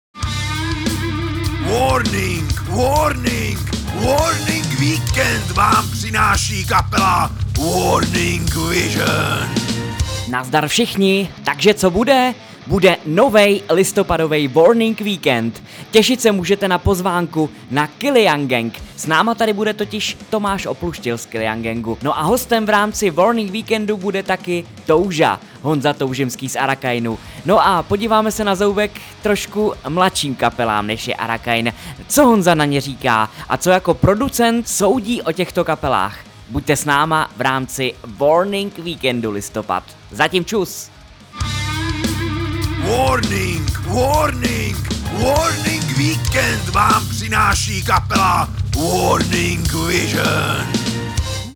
Sedmý pořad WV ve znamení velkého „A“, rozhovor nejen o Arakainu s jejich frontmanem Honzou Toužimským.